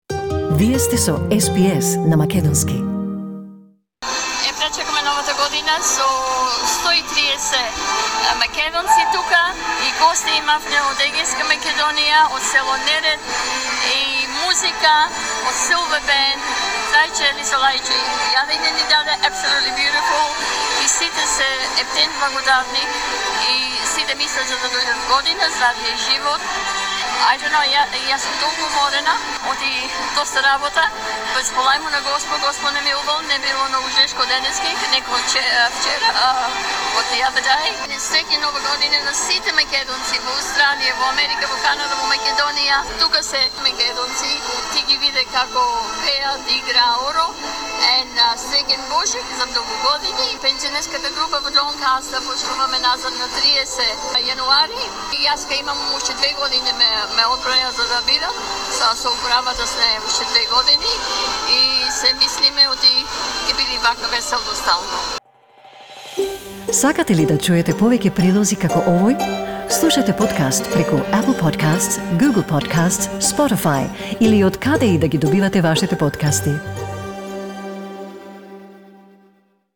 Members young and old held hands and stayed true to the age old drum beats as the new year replaced the old.
Over 130 members of the Macedonian Pensioner Group in Doncaster attended the New Year's event at the Ajani Centre in Lower Templestowe, where guests were treated to non-stop traditional dances by Silver Band well into the first day of 2020.